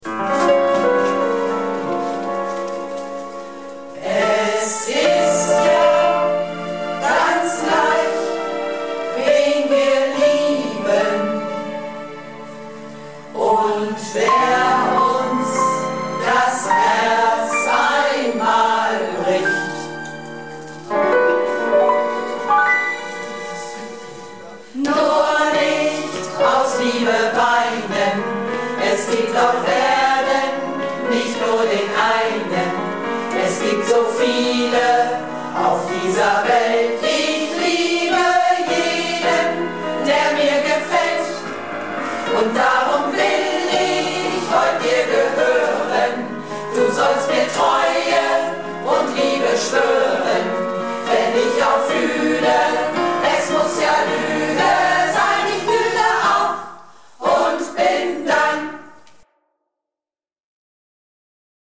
Projektchor "Keine Wahl ist keine Wahl" - Probe am 26.03.19